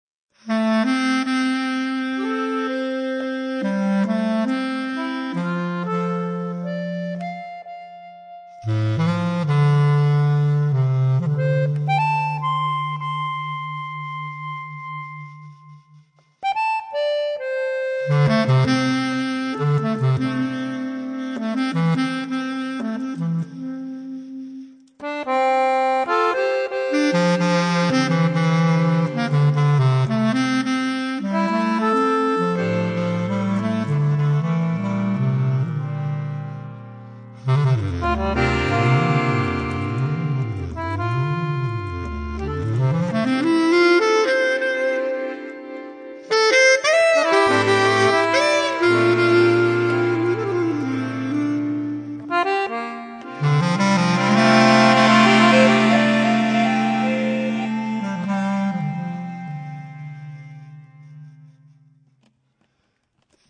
chitarra
fisarmonica
clarinetto e clarinetto basso
contrabbasso
batteria e percussioni